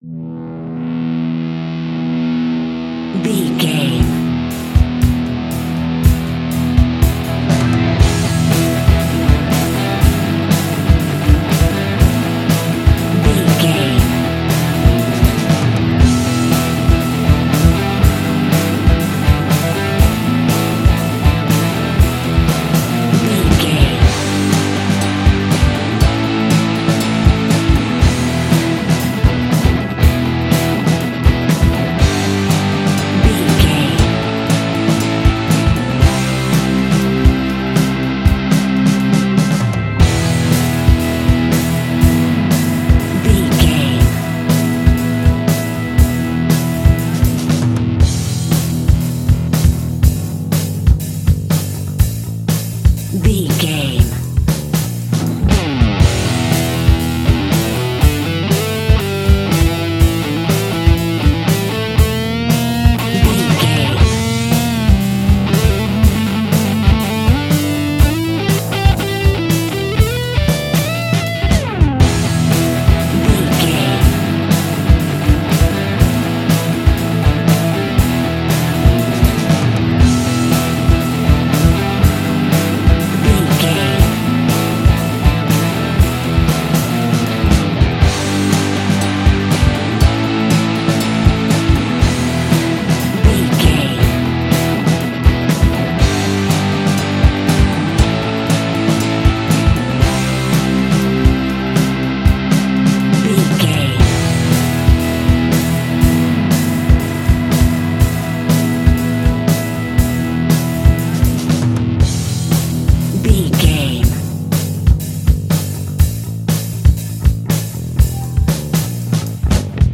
Aeolian/Minor
groovy
powerful
electric guitar
bass guitar
drums
organ